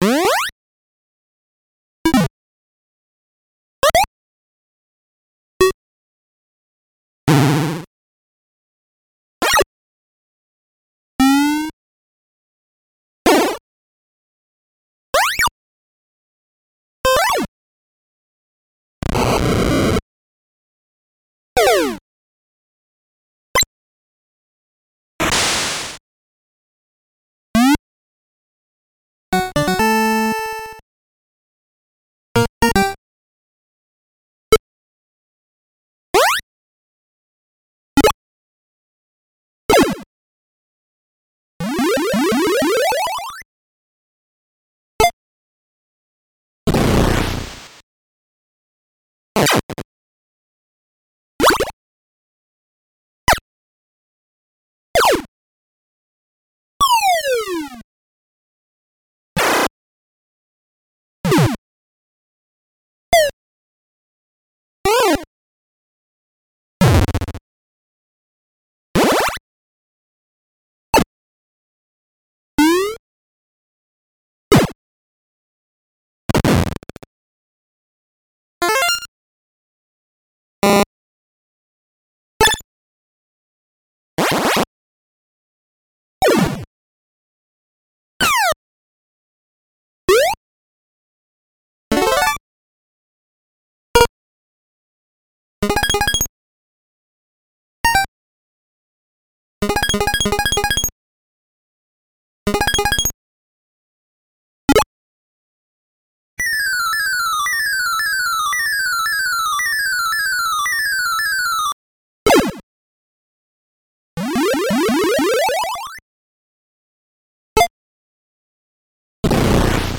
2000 Original 8-bit Video Games Sound Effects